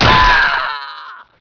death1.wav